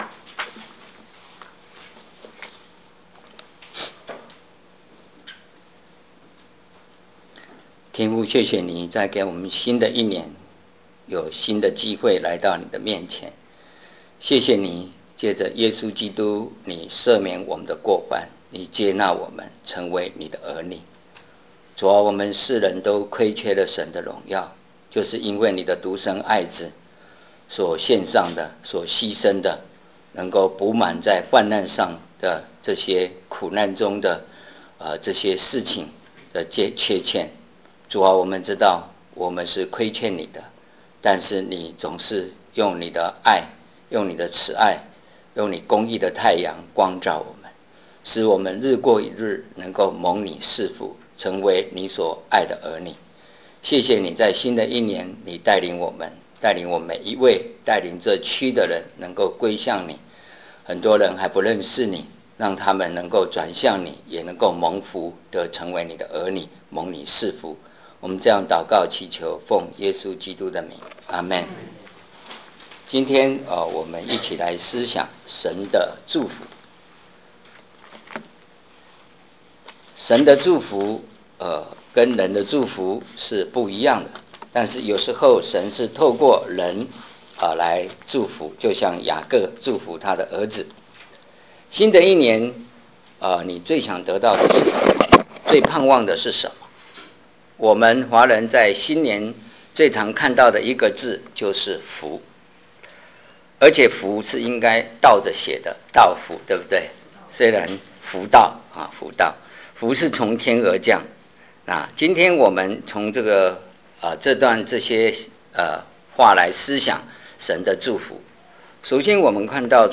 如何播放布道录音